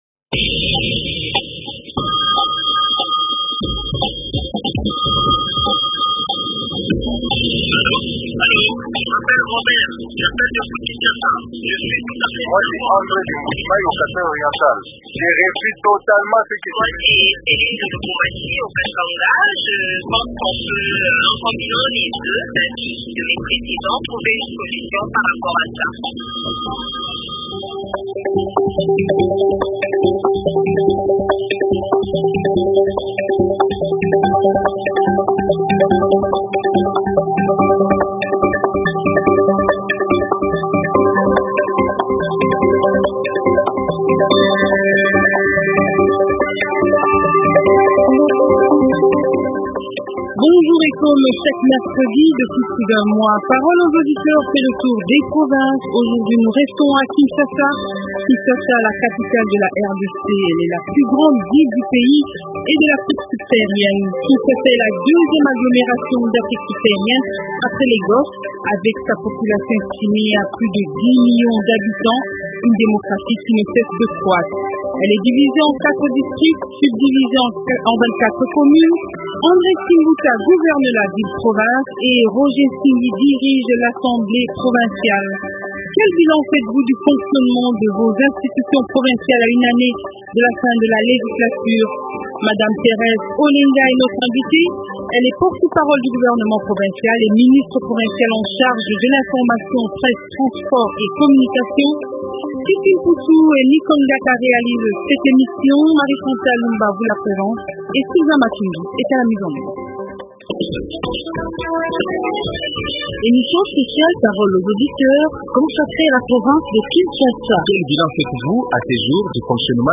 - Quel bilan faites-vous du fonctionnement de vos institutions provinciales à une année de la fin de législature ? Invité : Thérèse Olenga, porte-parole du gouvernement provincial et ministre provinciale en charge de l’Education, Environnement, Communication et Genre.